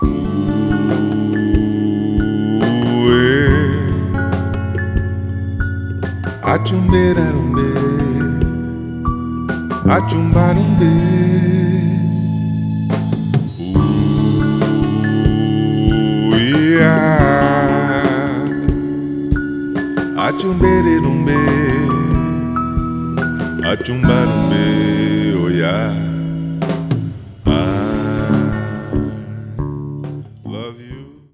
Teclado/Baixo/Bateria/Percussão/Backing Vocal